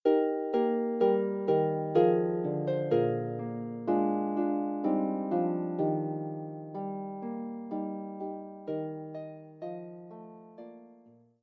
arranged for solo lever or pedal harp